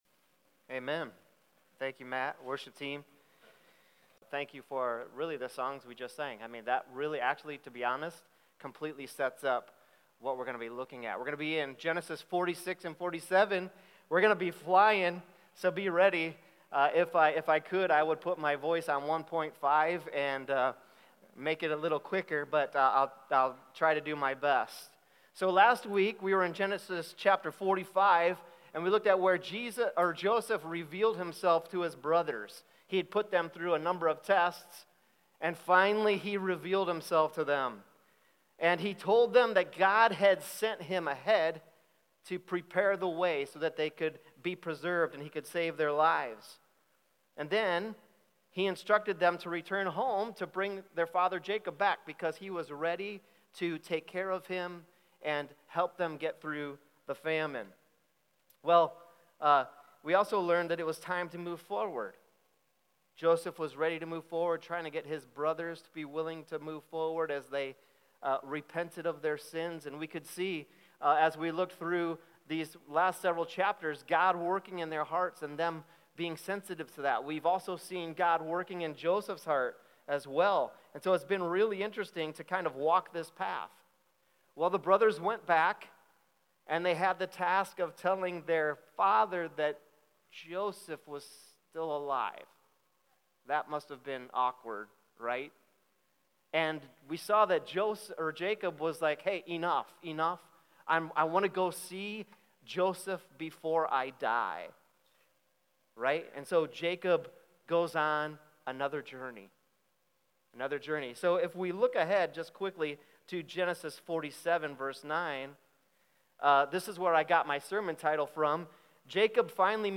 Sermon Questions.